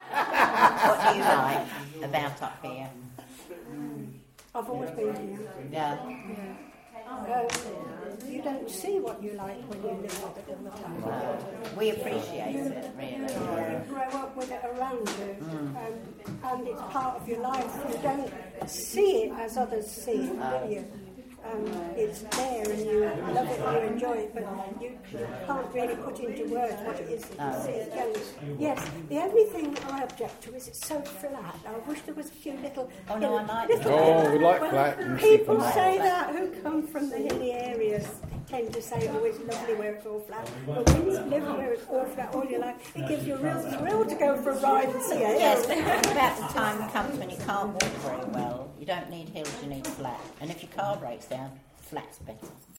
Reflecting on the landscape at Sutton St James good companions group